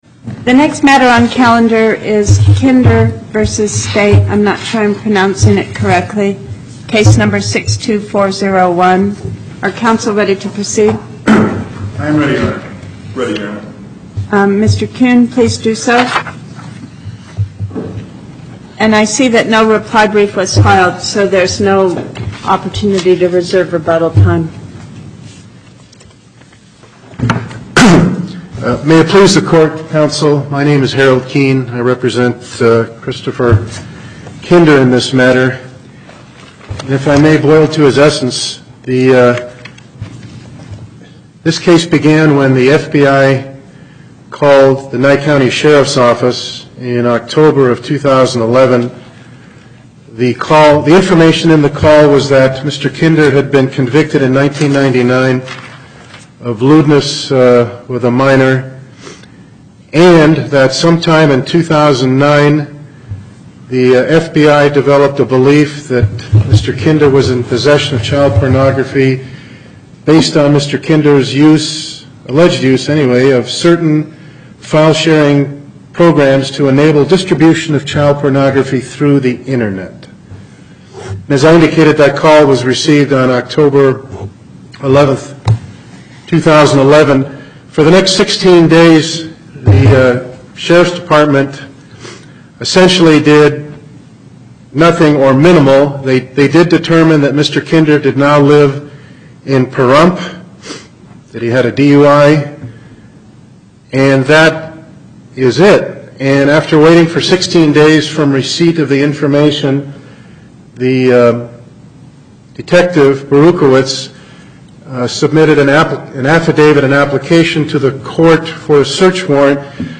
Loading the player Download Recording Docket Number(s): 62401 Date: 01/15/2014 Time: 11:30 A.M. Location: Carson City Before the Northern Nevada Panel. Justice Pickering Presiding Appearances